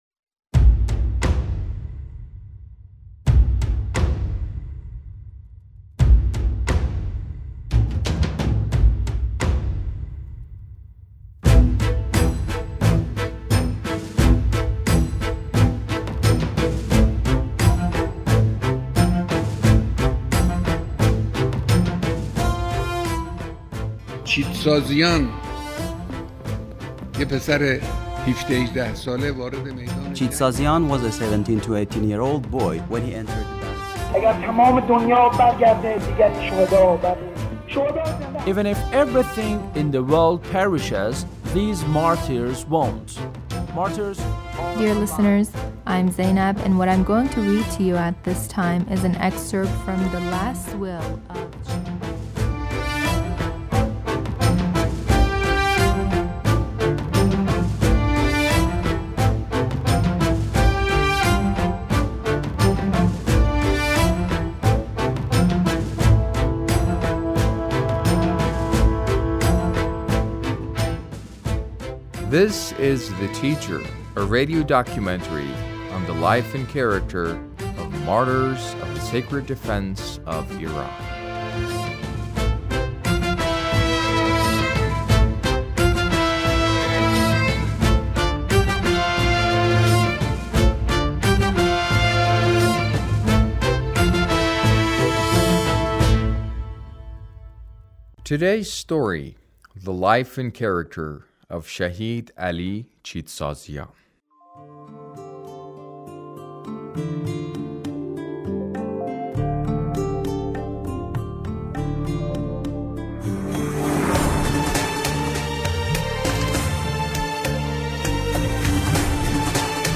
A radio documentary on the life of Shahid Ali Chitsaziyan - Part 1